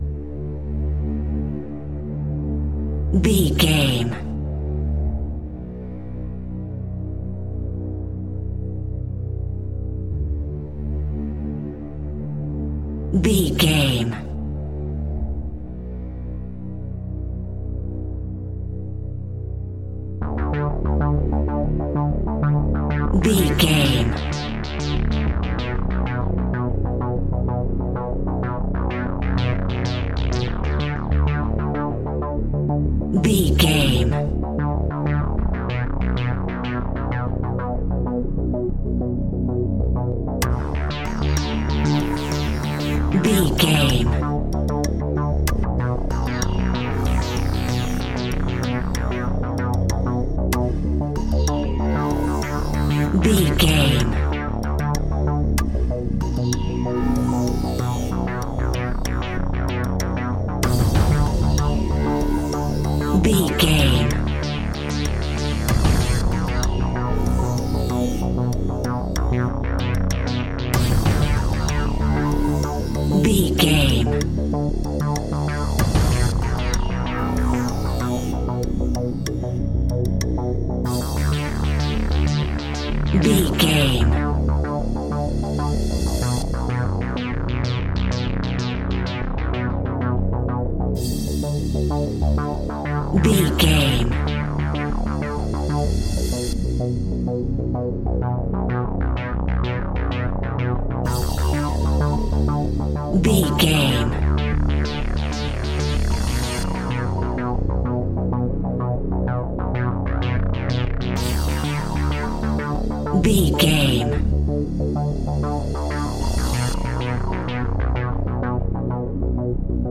Aeolian/Minor
tension
ominous
dark
haunting
eerie
industrial
cello
synthesiser
percussion
drums
horror music
horror instrumentals